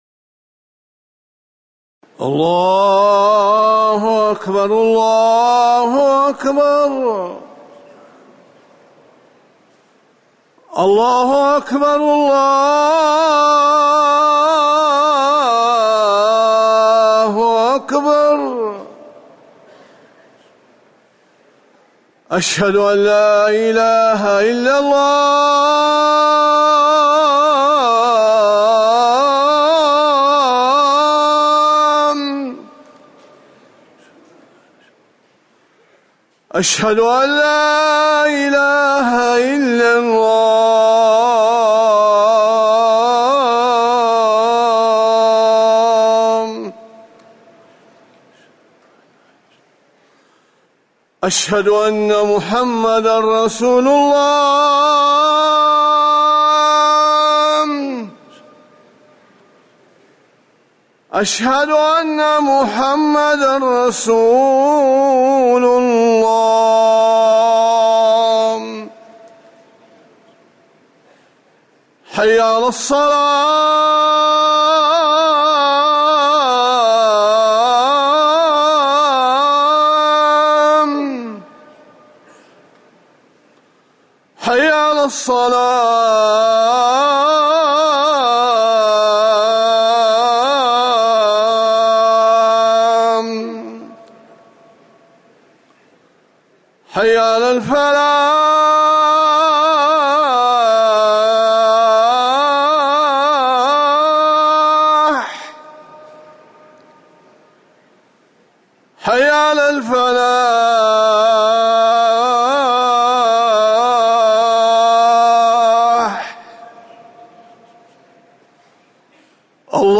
أذان المغرب
تاريخ النشر ٢٤ صفر ١٤٤١ هـ المكان: المسجد النبوي الشيخ